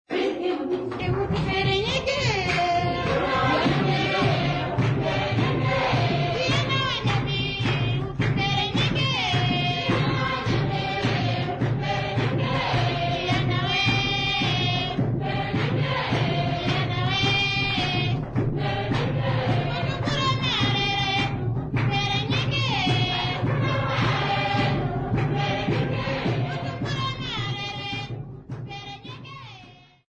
Andara music workshop participants
Folk music
Sacred music
Field recordings
Africa Namibia Andara sx
Kwangali traditional religious song accompanied by drums and clapping